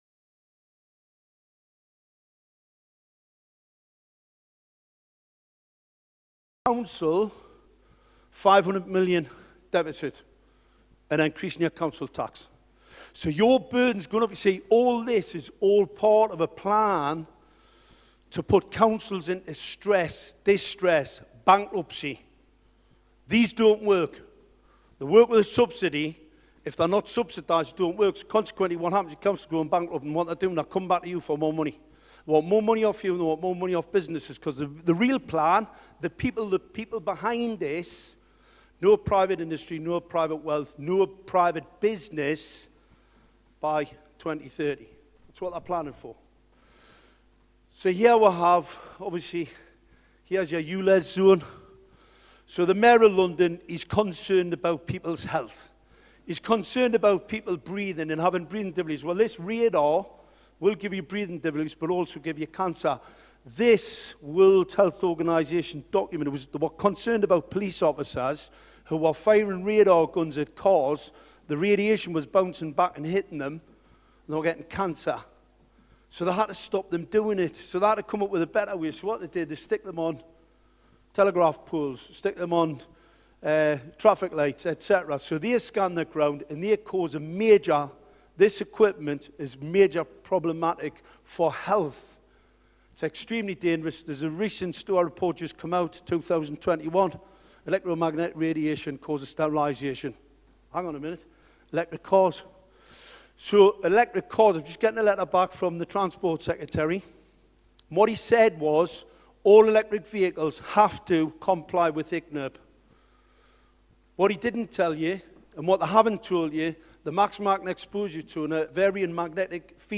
Anti WEF event London